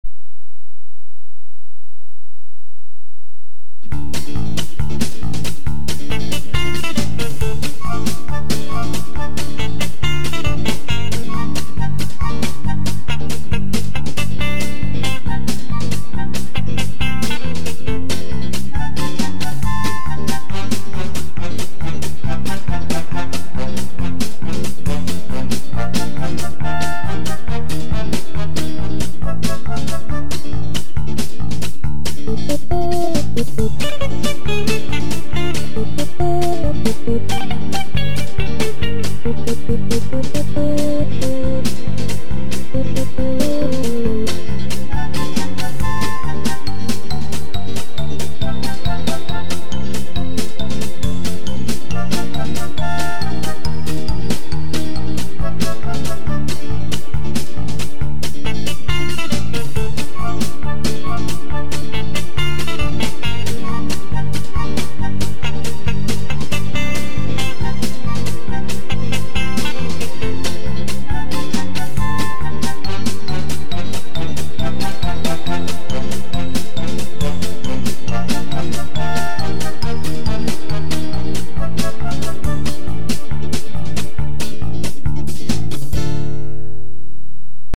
Слушать или скачать минус к песне